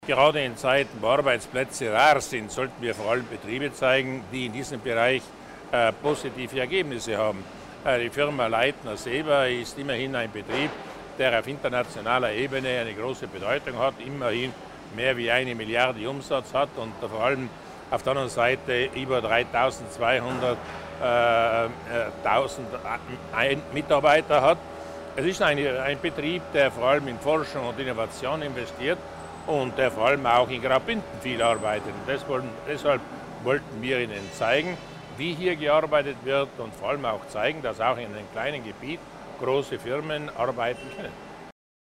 Landeshauptmann Durnwalder Über die Vorteile der Zusammenarbeit mit Graubünden